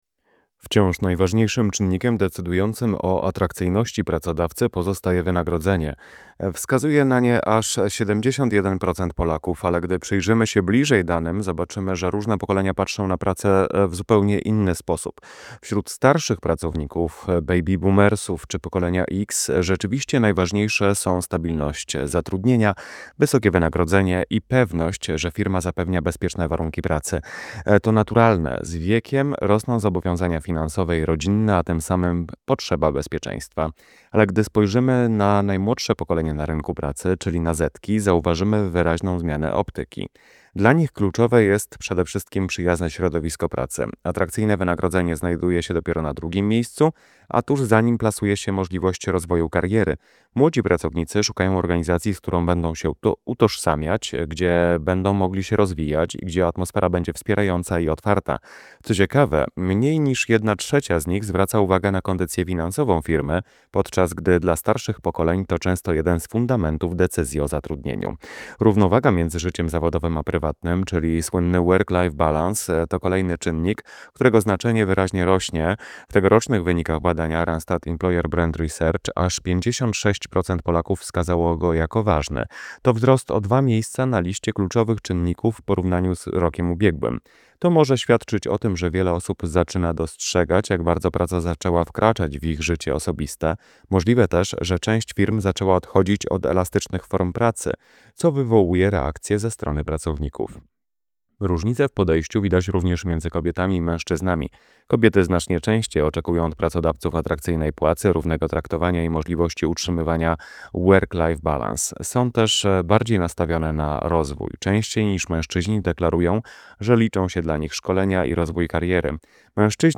komentarz audio
Czynniki atrakcyjności pracodawców REBR - komentarz audio.mp3